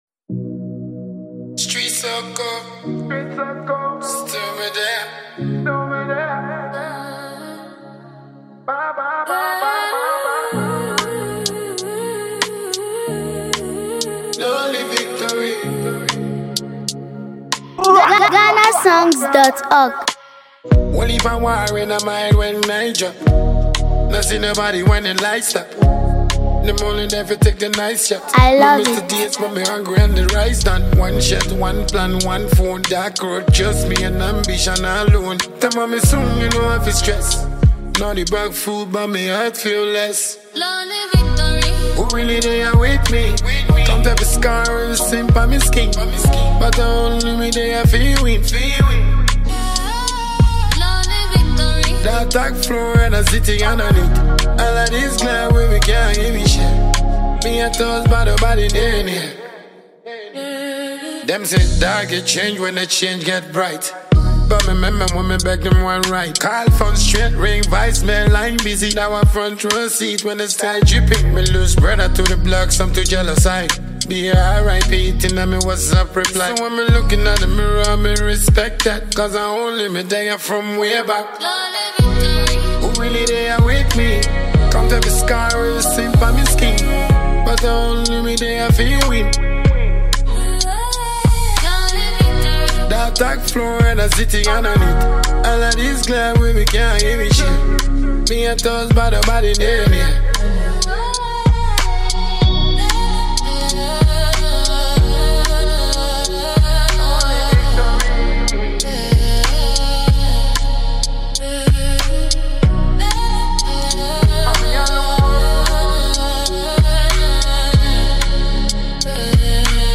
deeply emotional and motivational song
With heartfelt lyrics and a calm yet powerful delivery